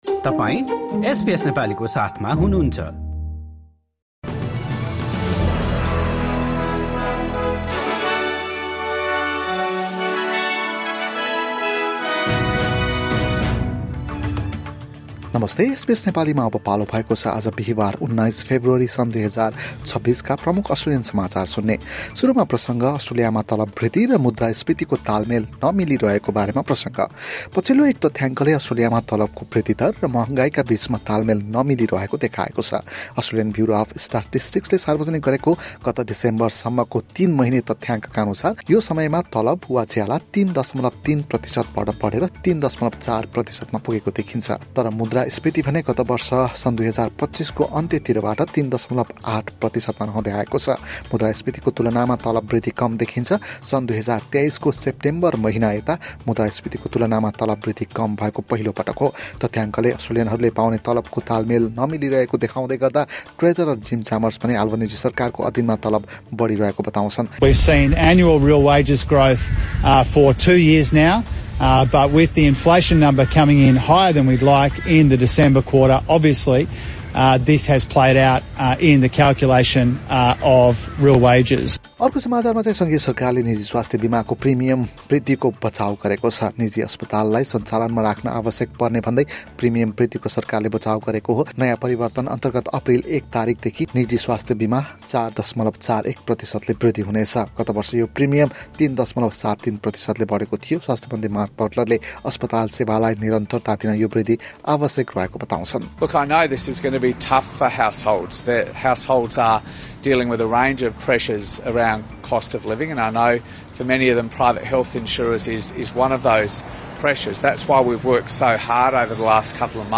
SBS Nepali Australian News Headlines: Thursday, 19 February 2026